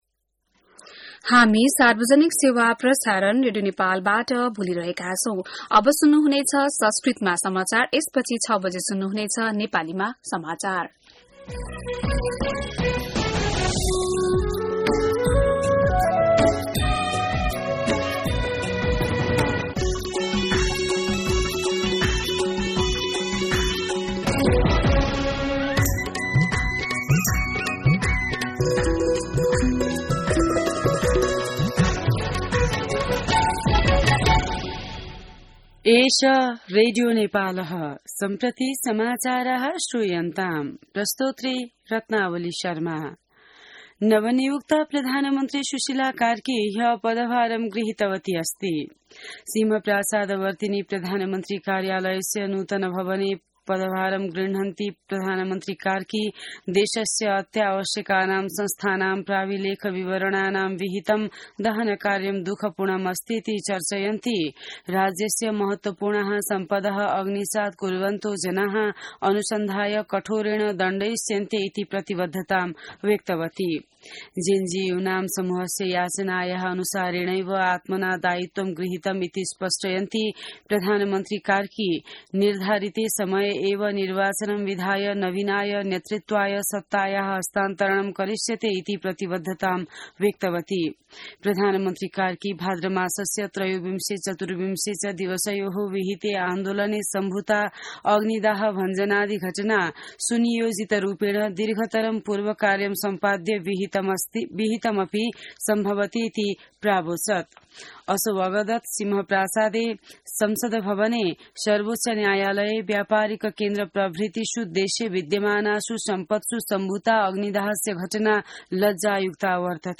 An online outlet of Nepal's national radio broadcaster
संस्कृत समाचार : ३० भदौ , २०८२